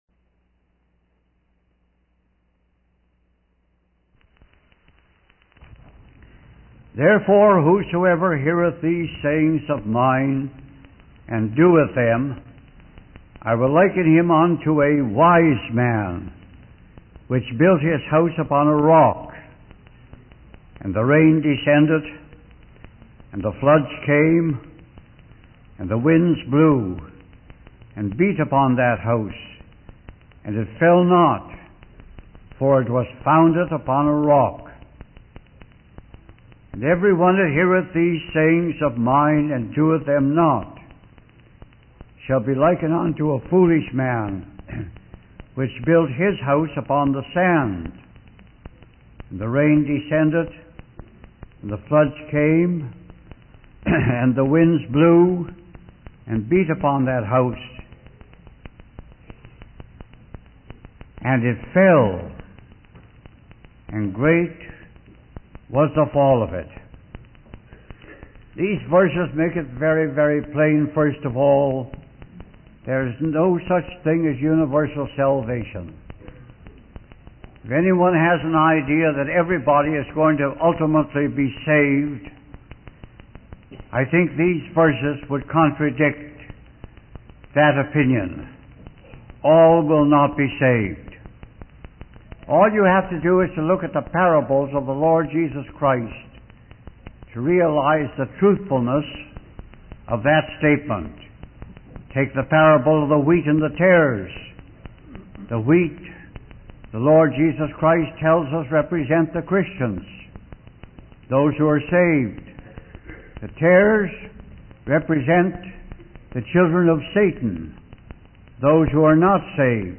In this sermon, the speaker emphasizes five solemn facts about salvation.